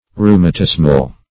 Rheumatismal \Rheu`ma*tis"mal\, a.
rheumatismal.mp3